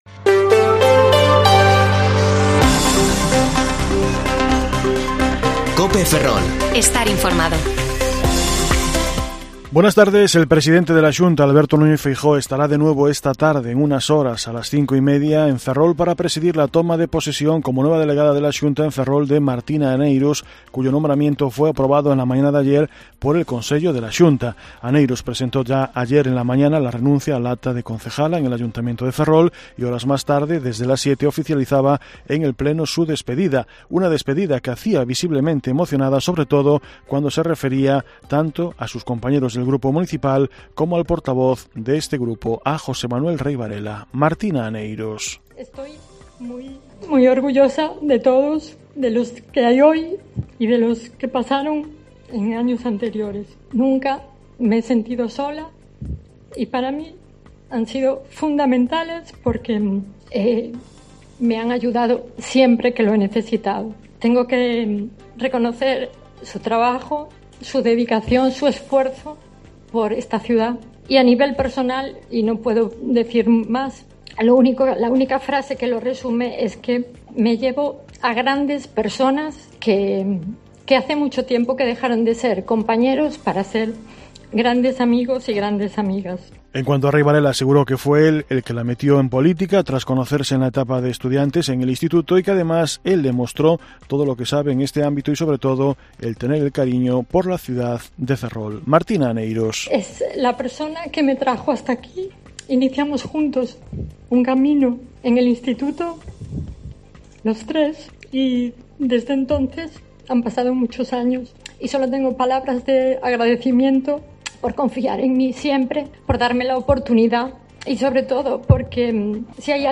Informativo Mediodía COPE Ferrol 28/1/2022 (De 14,20 a 14,30 horas)